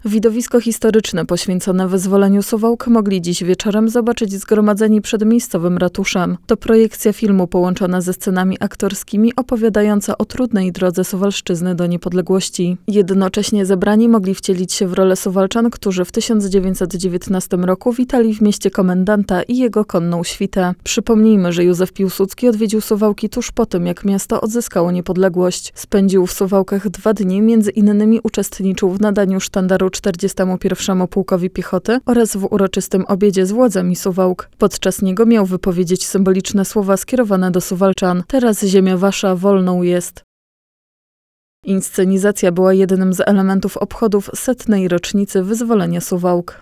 Widowisko historyczne, poświęcone wyzwoleniu Suwałk, mogli w sobotę (24.08.19) wieczorem zobaczyć zgromadzeni przed miejscowym ratuszem. To projekcja filmu, połączona ze scenami aktorskimi, opowiadającego o trudnej drodze Suwalszczyzny do niepodległości.
widowisko-pod-ratuszem-na-sobote.wav